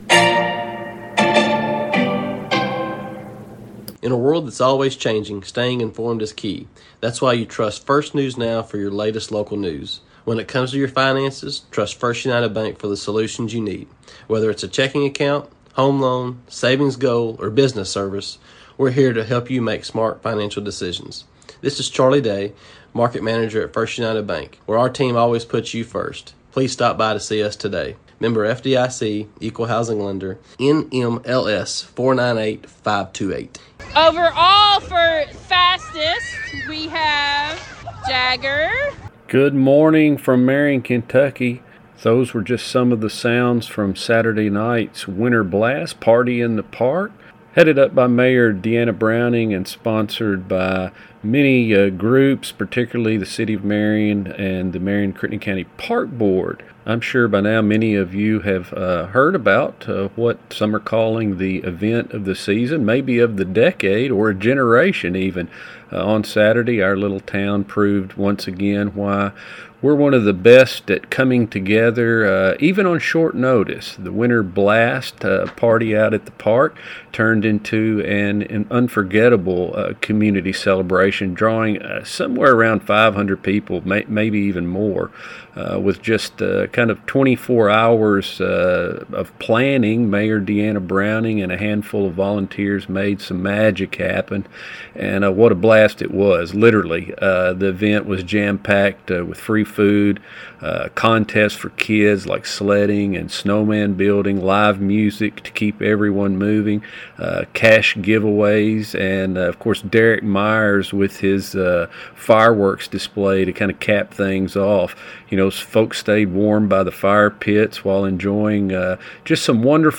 interviewing Mayor Browning during Party in the Park